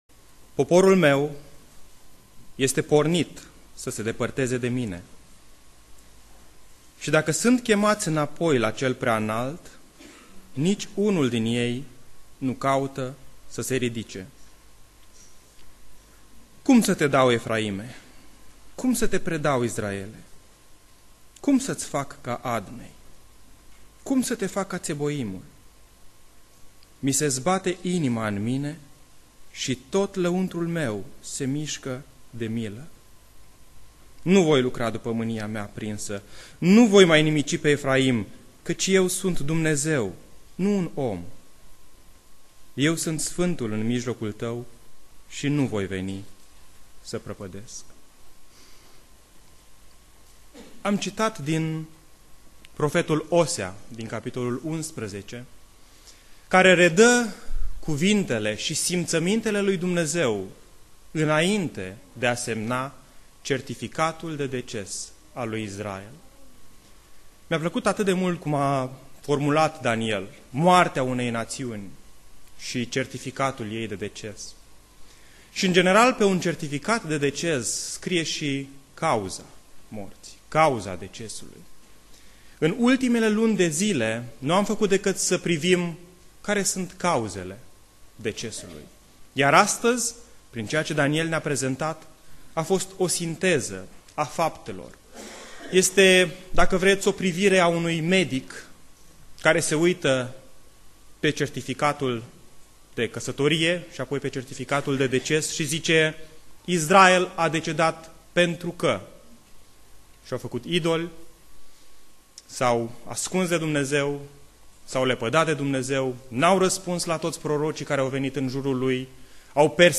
Predica Aplicatie 2 Imparati cap. 17